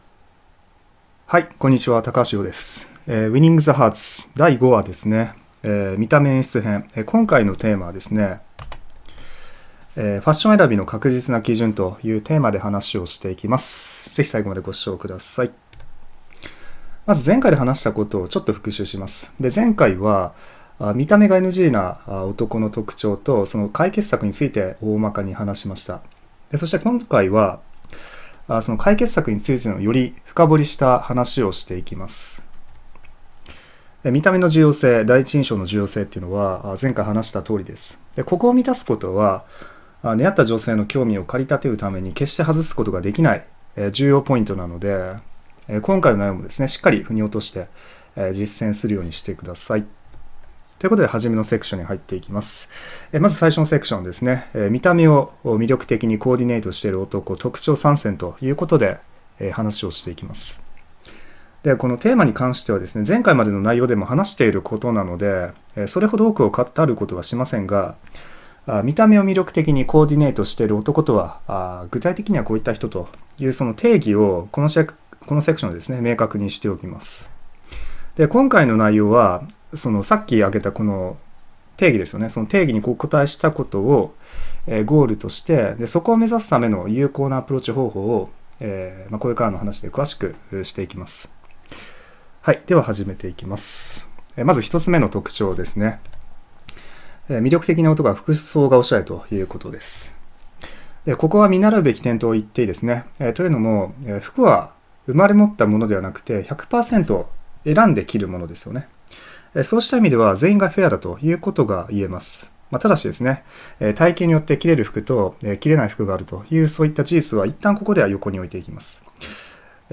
【第５話】ファッション選びの確実な基準 ※こちらは、レポート内容を 講義形式 にした 動画 です。